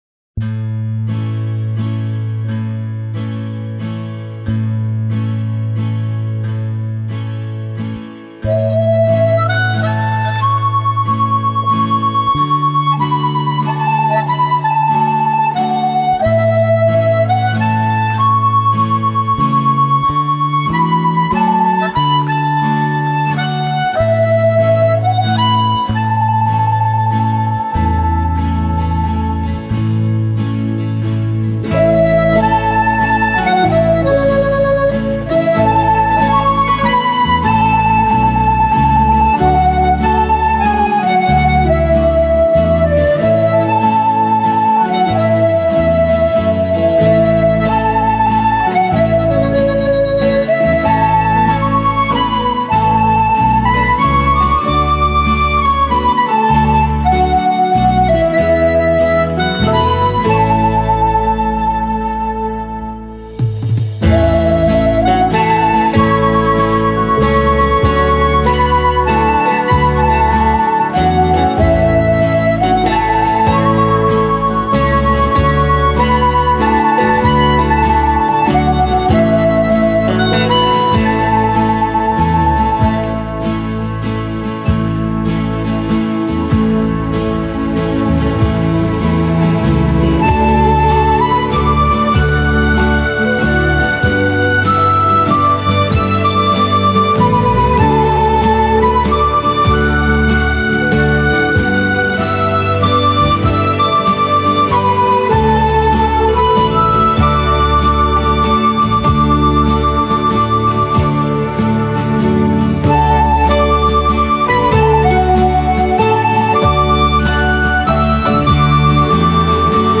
Genre:Instrumenta